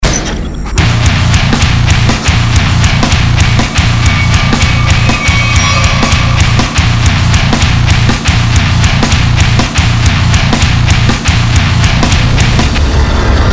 industrial metal test (POD X3/MF/amplitube/)